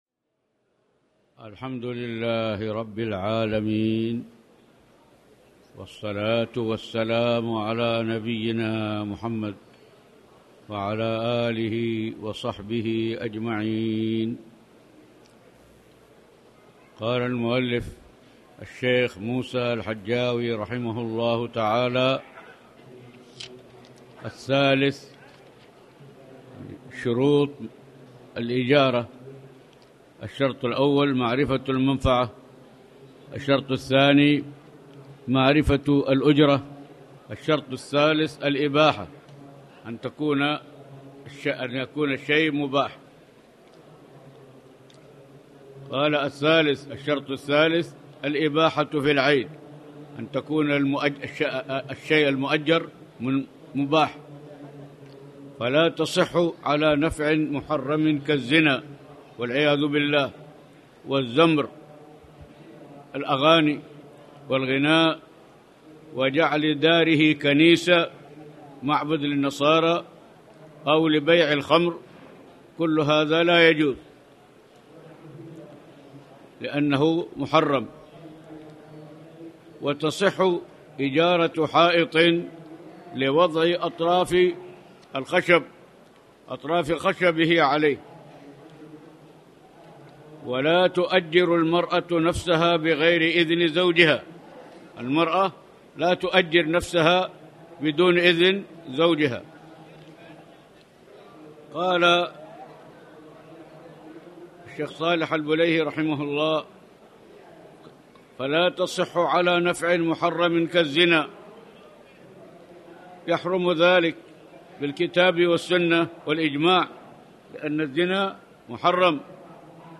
تاريخ النشر ١ ربيع الثاني ١٤٣٩ هـ المكان: المسجد الحرام الشيخ